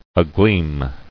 [a·gleam]